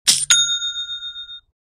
chaching.mp3